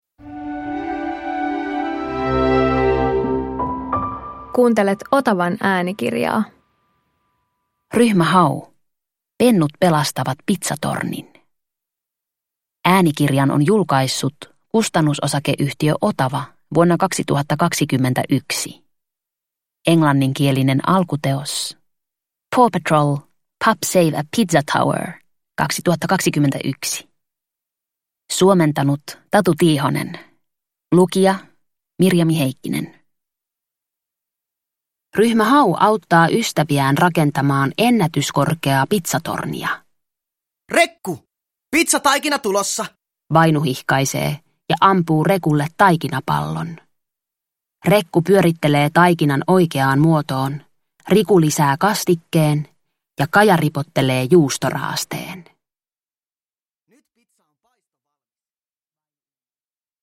Ryhmä Hau - Pennut pelastavat pitsatornin – Ljudbok – Laddas ner
Vauhdikas äänikirja Ryhmä Haun seikkailuista. Pormestari Pujola ja Aleksi Totteri tavoittelevat maailmanennätystä: he haluavat valmistaa maailman korkeimman pitsatornin!